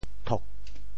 秃 部首拼音 部首 禾 总笔划 7 部外笔划 2 普通话 tū 潮州发音 潮州 têg4 文 tog4 白 潮阳 tog4 白 澄海 tog4 白 揭阳 tog4 白 饶平 tog4 白 汕头 tog4 白 中文解释 秃〈形〉 (象形。
thok4.mp3